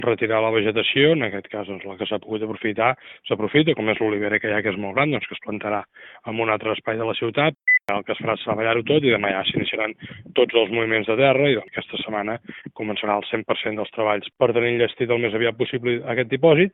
Ho ha confirmat l’alcalde Marc Buch en declaracions a Ràdio Calella TV.